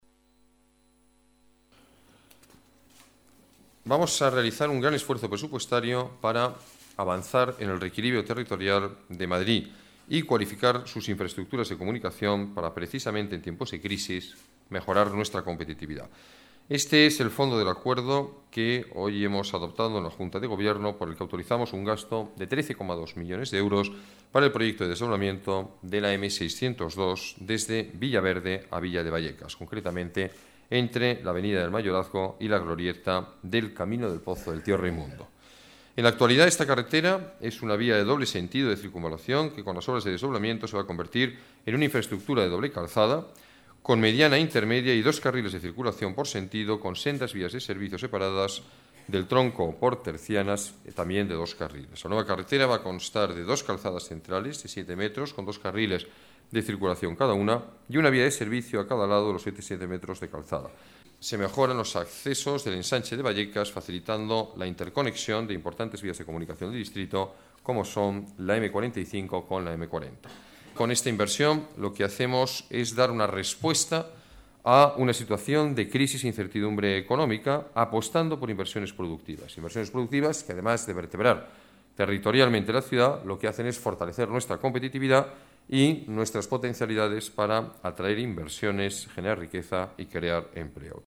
Nueva ventana:Declaraciones del alcalde sobre los beneficios de la nueva infraestructura